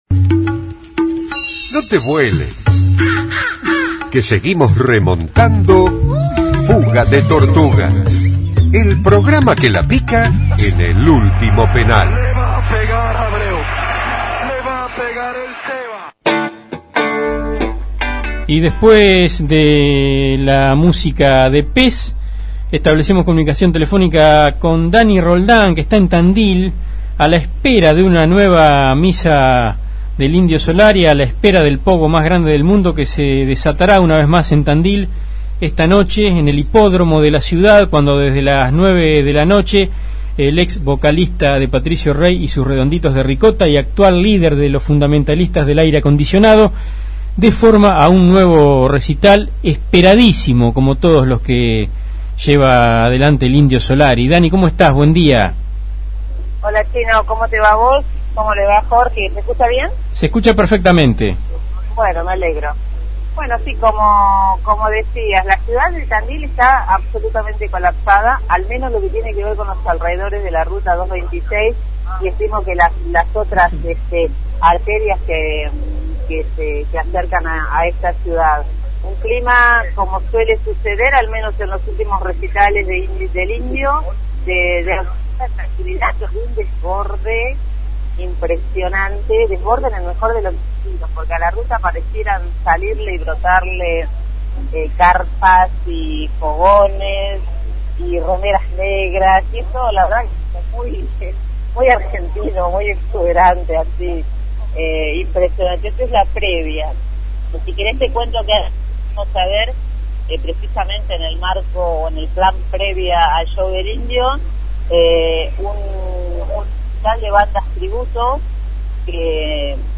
móvil desde Tandil